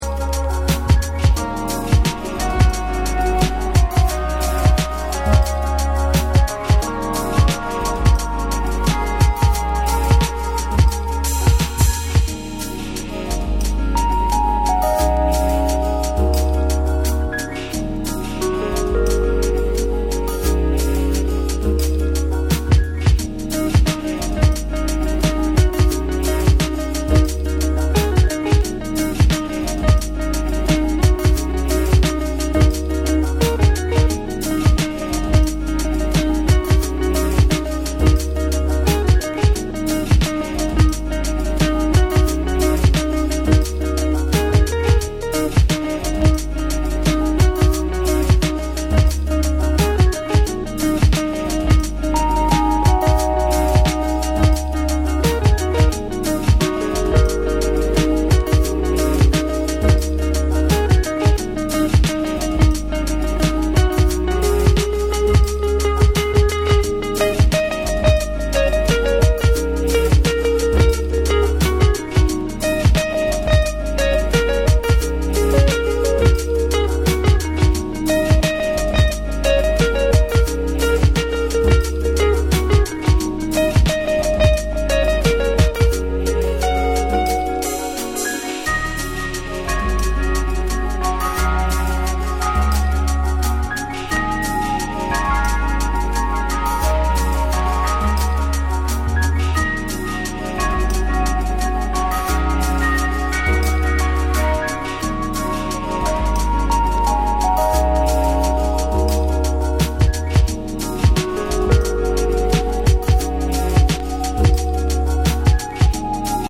Balearic sounds and downtempo chill music for this 7'.